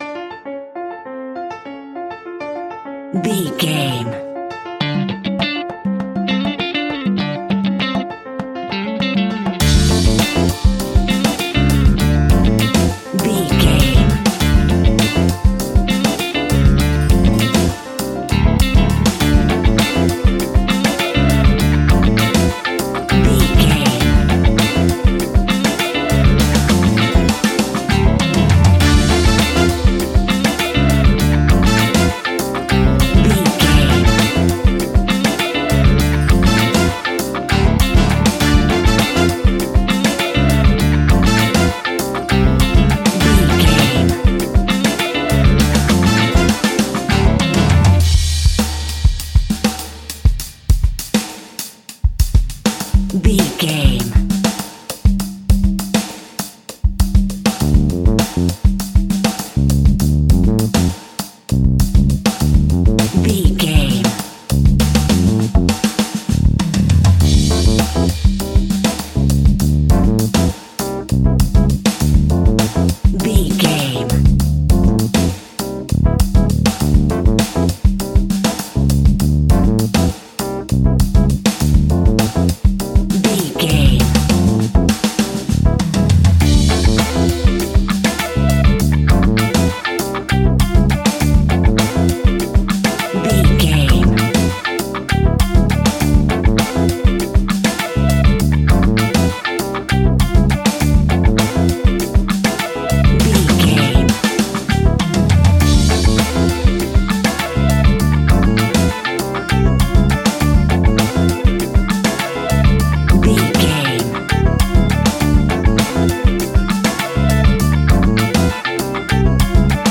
Aeolian/Minor
D
cuban music
World Music
brass
saxophone
trumpet
fender rhodes